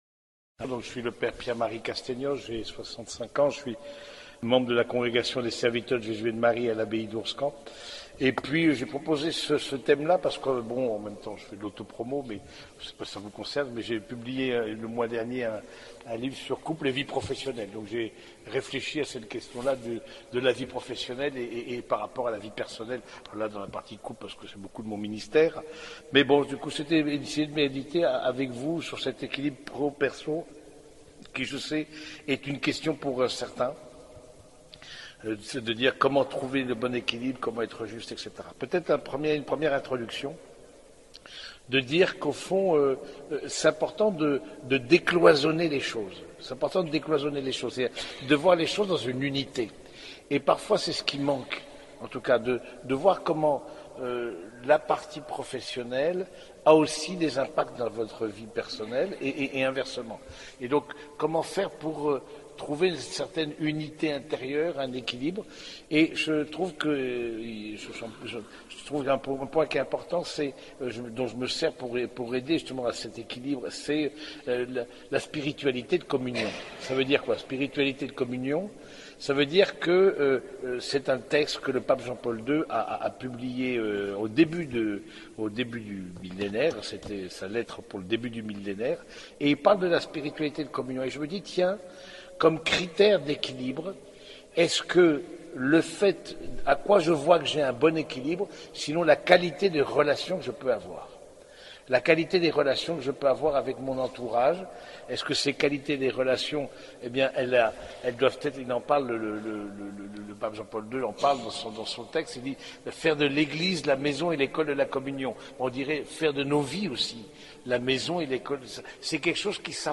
Conférence Spi&Spi de juin 2025